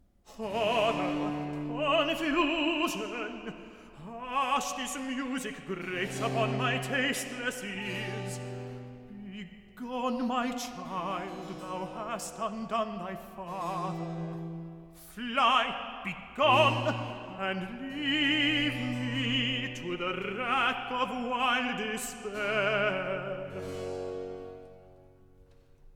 Recitative